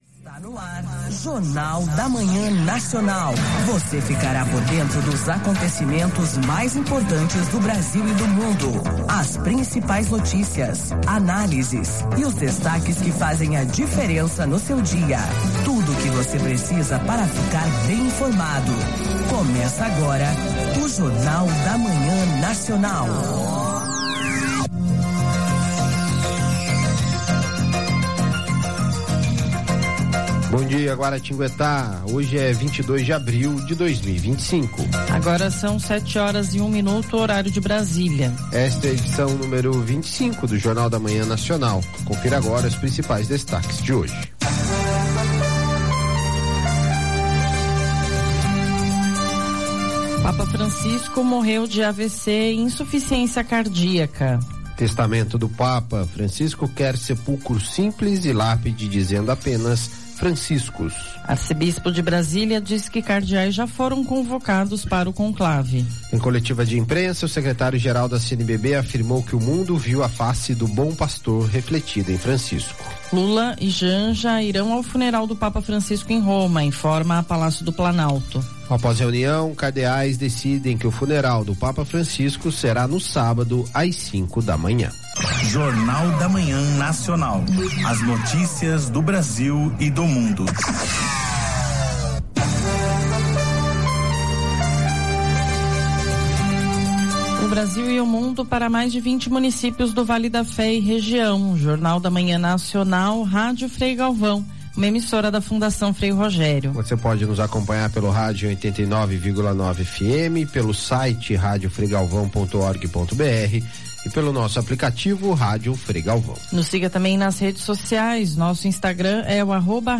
No programa, foram citados os detalhes divulgados pelo Vaticano a respeito do funeral do Papa Francisco e a causa de sua morte. A edição também trouxe uma entrevista exclusiva com o arcebispo emérito de Aparecida, o cardeal Dom Raymundo Damasceno. Ele partilhou seu duplo sentimento com a notícia, a tristeza pela despedida mas a alegria pelo cumprimento da missão do argentino no ministério petrino.
A edição especial também trouxe uma participação inédita de Dom Orlando Brandes, arcebispo de Aparecida.